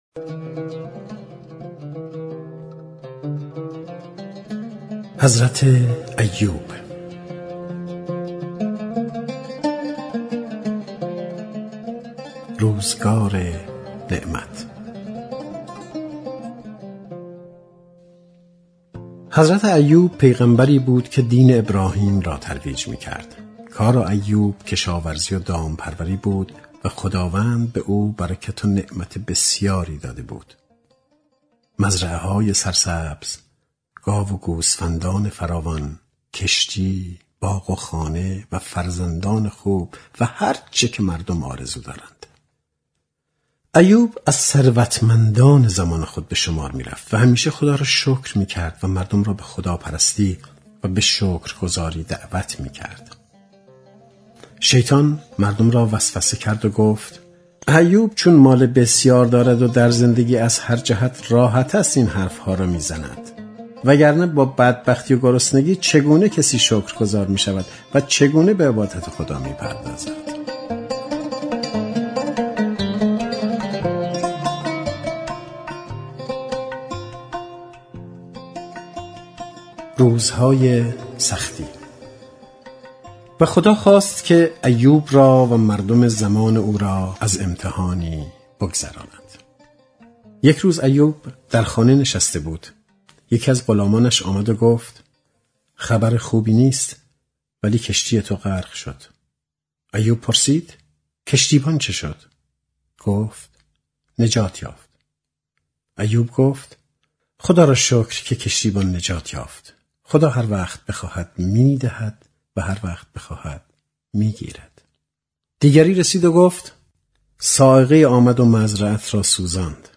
بر همین اساس نسخه‌ الکترونیک کتاب‌های صوتی «قصه‌های قرآن» با صدای مصطفی رحماندوست از طریق اپلیکیشن رایگان فیدیبو در دسترس علاقه‌مندان قرار گرفت.
کتاب صوتی قصه های قرآن ۱